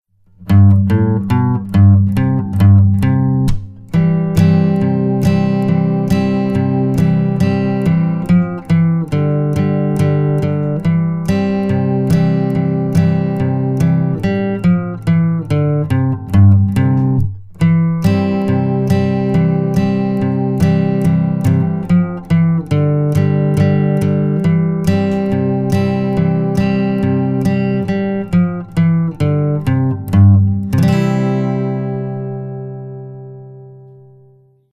song_1_music_only.mp3